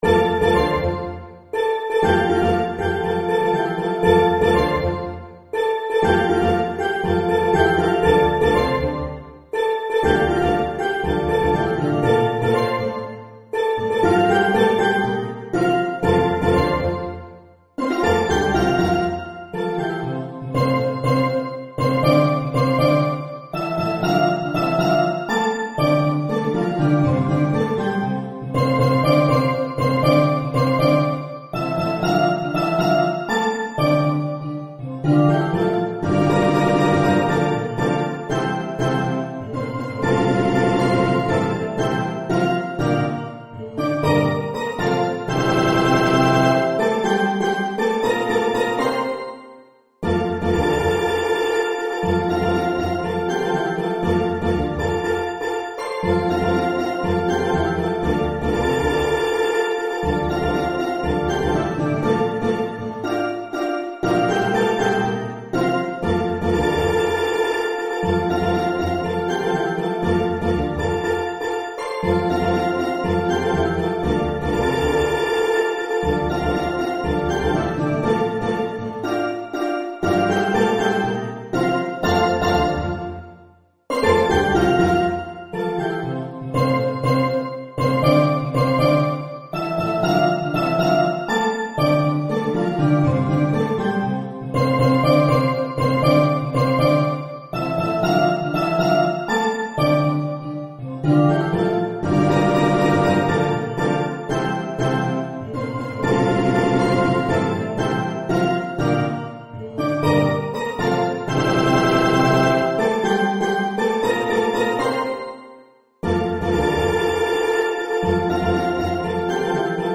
Mallet-Steelband